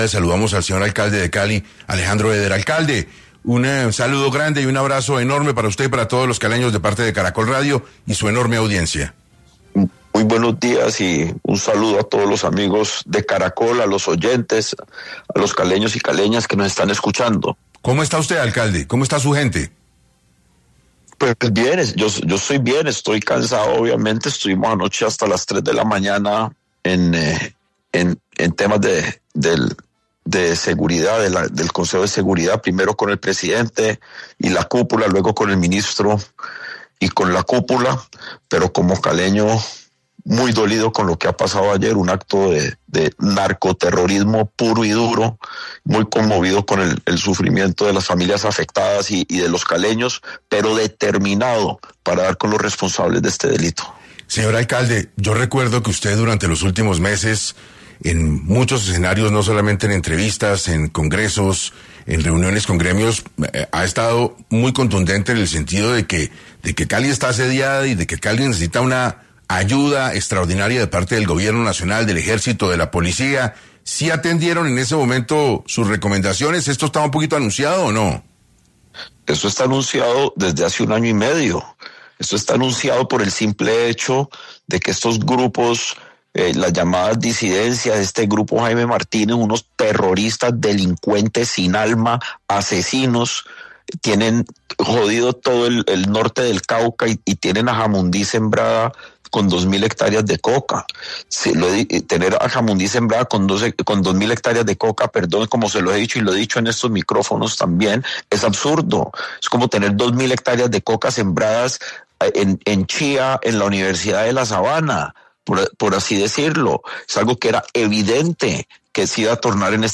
Alejandro Eder habló en 6AM sobre el dolor en Cali tras el atentado, y Germán Escobar, secretario de Salud explicó el estado de salud de los 76 heridos por la explosión en inmediaciones de la base aérea Marco Fidel Suárez.
Ahora bien, el alcalde Alejandro Eder conversó con Caracol Radio sobre el dolor en Cali tras el atentado.